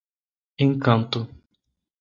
/ẽˈkɐ̃.tu/